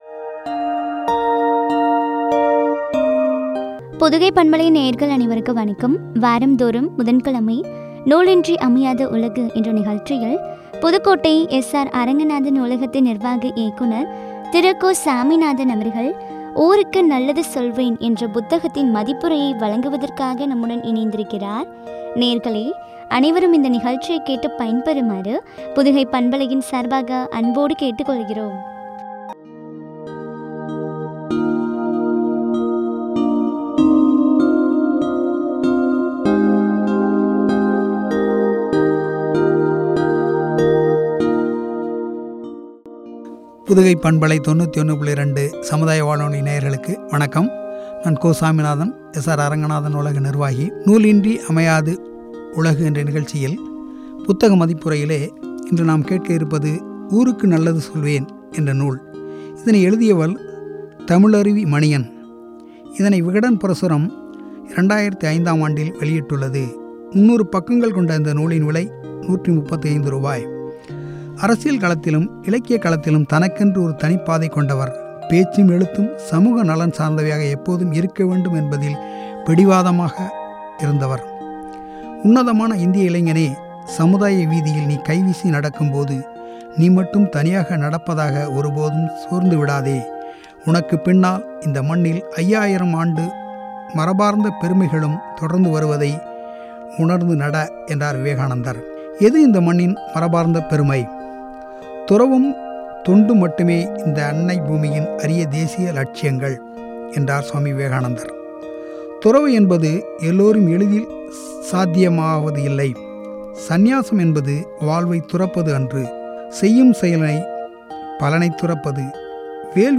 “ஊருக்கு நல்லது செய்வேன்!” புத்தக மதிப்புரை (பகுதி -12)